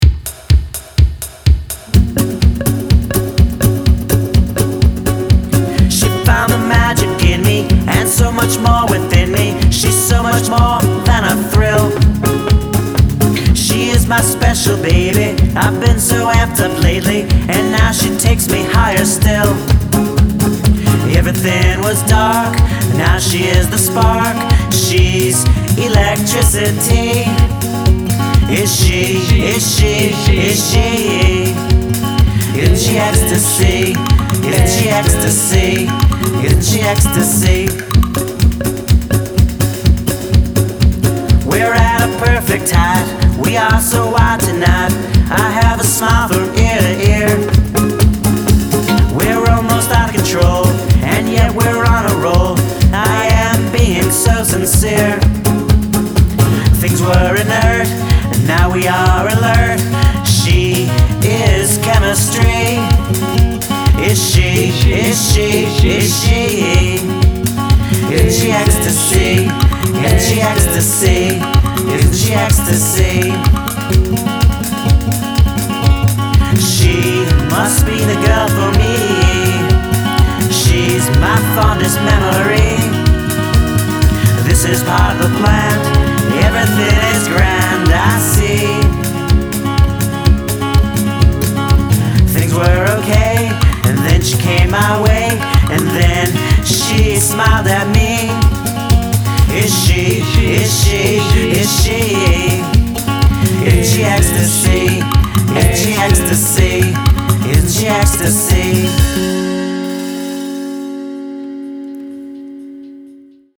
boston's power duo